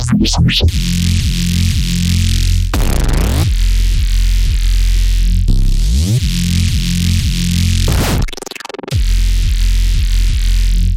Tag: 175 bpm Drum And Bass Loops Bass Wobble Loops 1.85 MB wav Key : Unknown